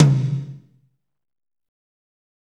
Index of /90_sSampleCDs/Northstar - Drumscapes Roland/DRM_Fast Rock/TOM_F_R Toms x
TOM F RHI0DR.wav